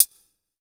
• Small Reverb High-Hat Sound D Key 48.wav
Royality free hi-hat sample tuned to the D note. Loudest frequency: 9612Hz
small-reverb-high-hat-sound-d-key-48-vsG.wav